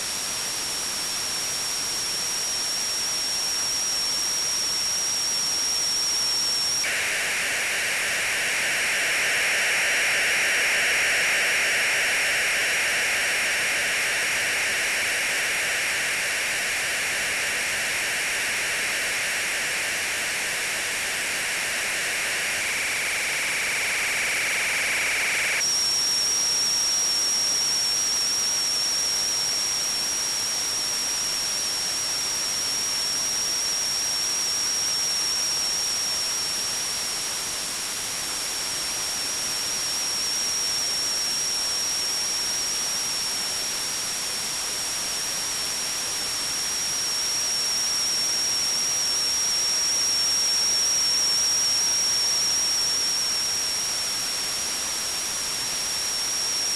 Начало » Записи » Радиоcигналы на опознание и анализ